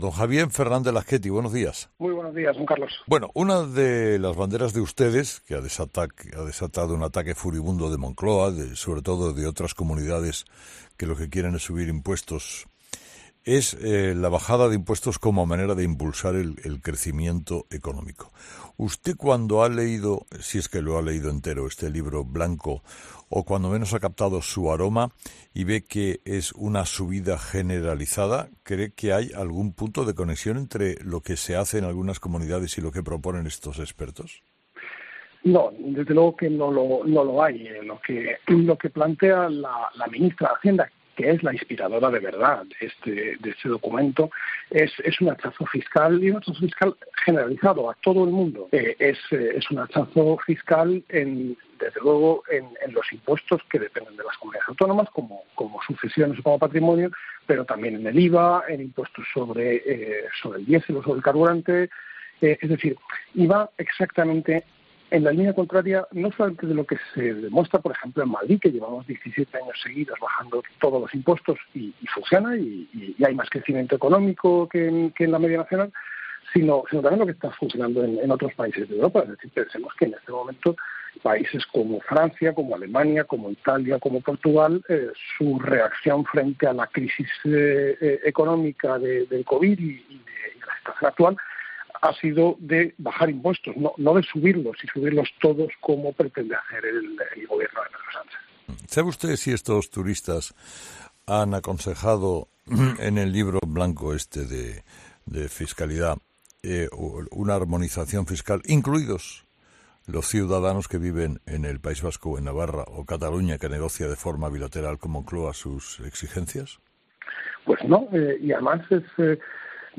Libro blanco de reforma fiscal, bajada de impuestos e inflación: claves de la entrevista a Lasquetty en COPE
A continuación, recordamos algunas de las claves y titulares de Lasquetty en la entrevista con Carlos Herrera: